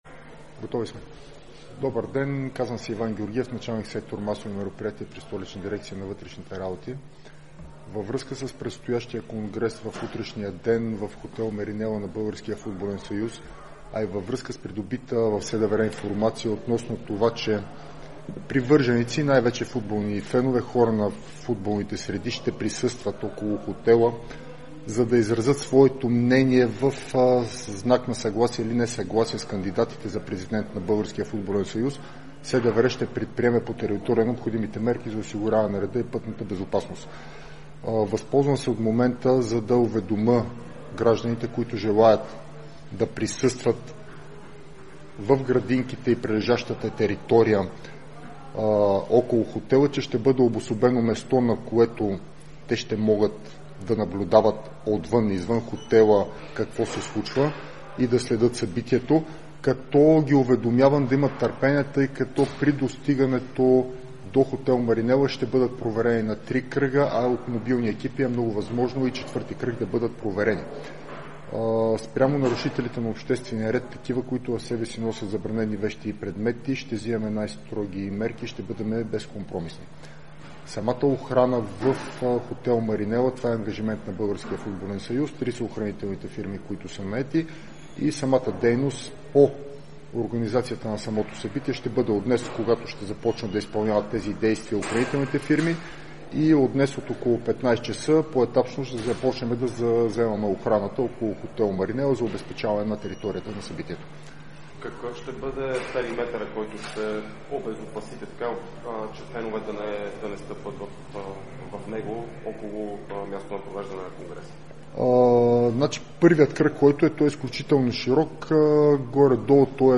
Информацията бе изнесена от Столичната дирекция на вътрешните работи на специална пресконференция.